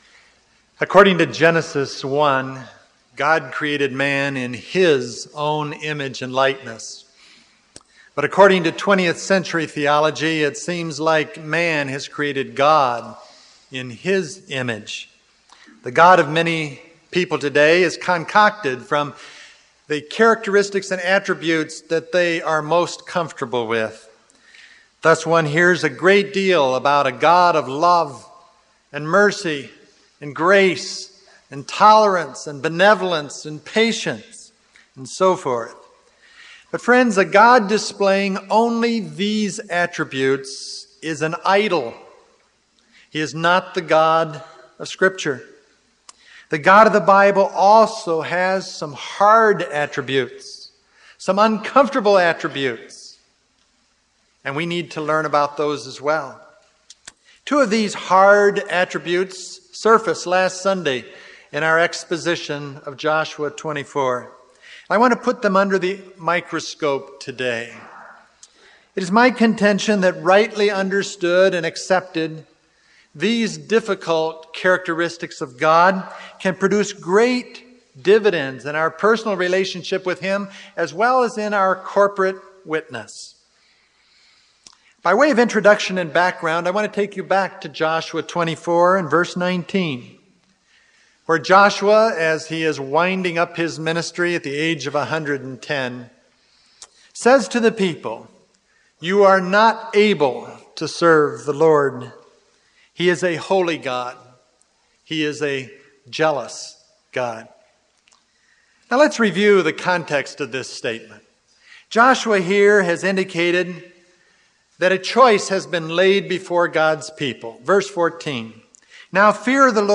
It is a sermon I did at Dallas Theological Seminary.